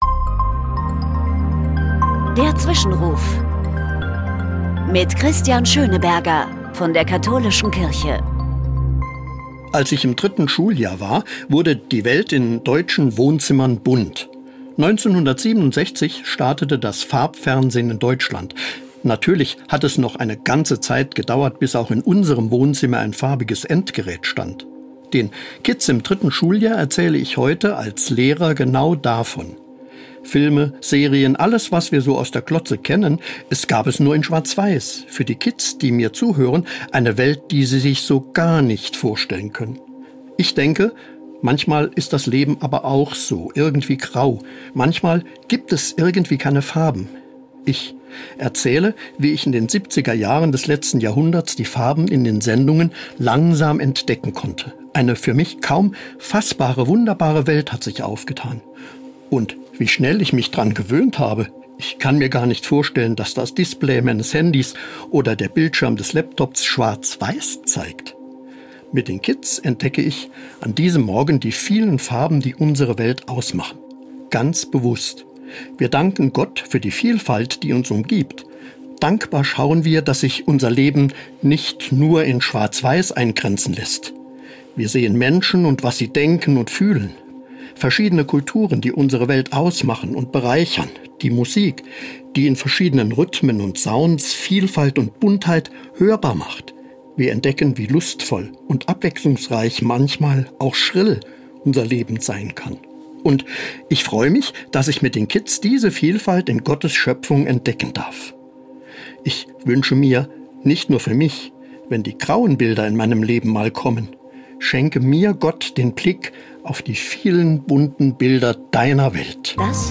Das christliche Wort zum Alltag